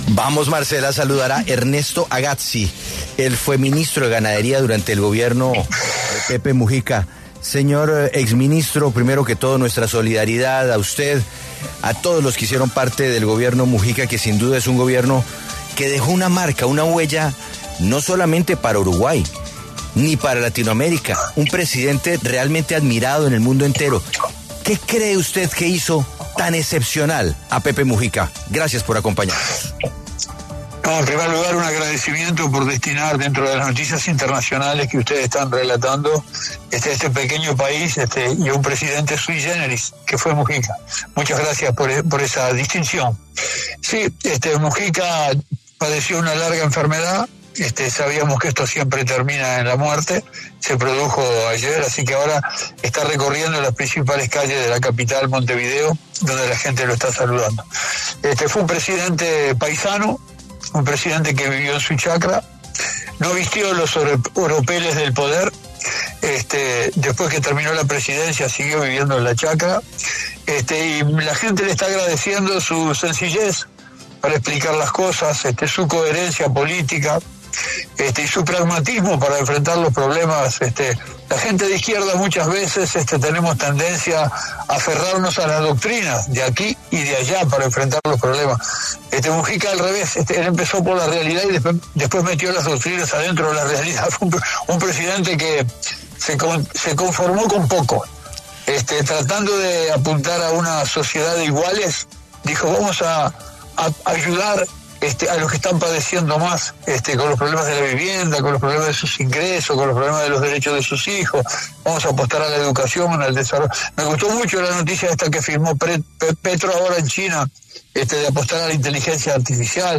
La W conversó con Ernesto Agazzi, ministro de Pepe Mujica, quien destacó la labor política y social del expresidente en Uruguay.